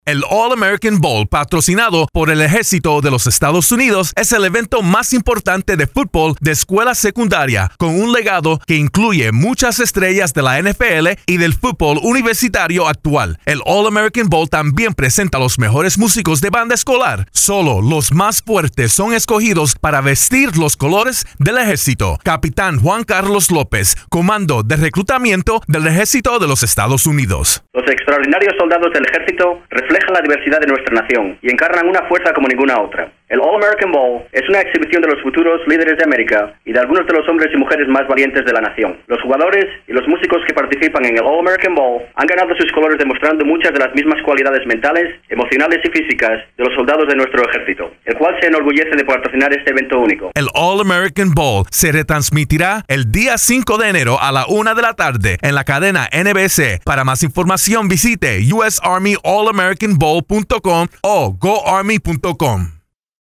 December 28, 2012Posted in: Audio News Release